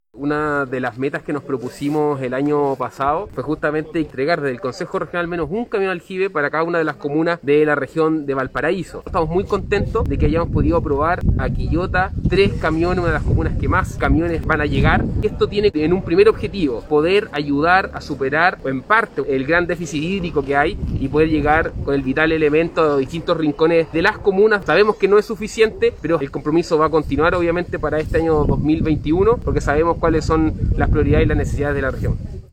Por ello, el consejero por la Provincia de Quillota, Cristian Mella Andaúr, también estuvo presente en la recepción de los camiones y detalló el origen y objetivos de esta importante inversión.
03-CORE-MELLA-Compromiso-del-Core-1.mp3